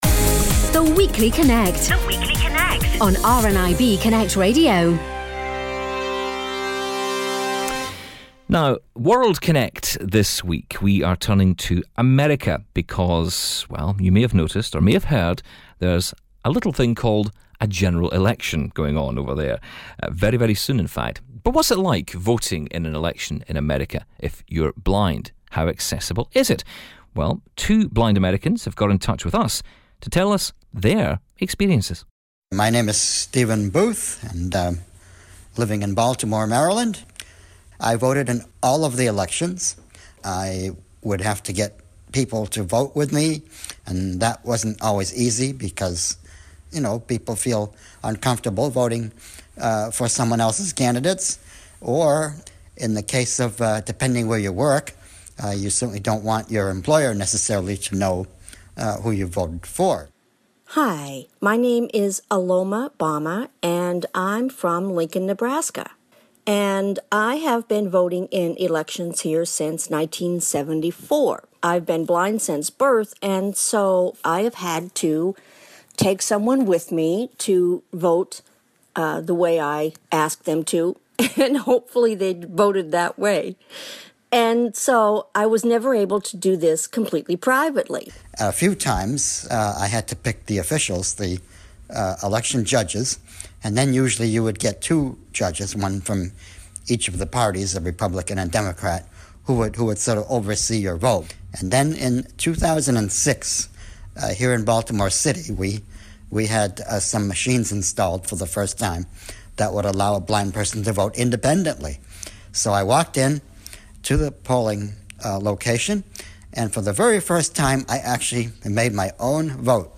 As the US election draws nearer we hear from two blind Americans on how accessible the voting process is in the States.